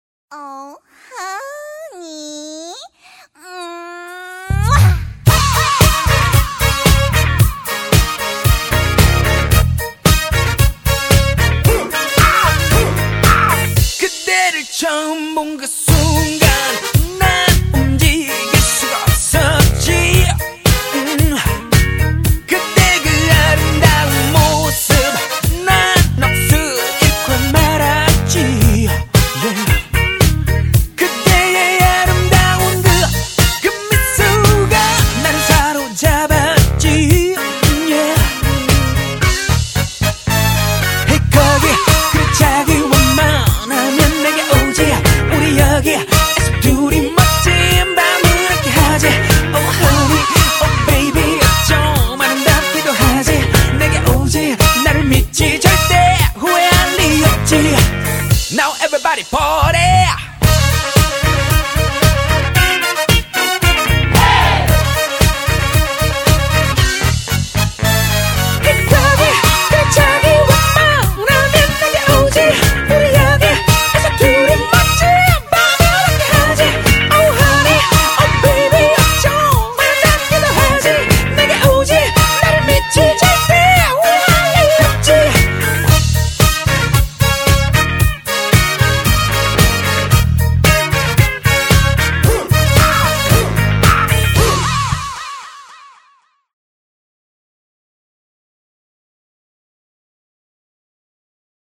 BPM113--1
Audio QualityPerfect (High Quality)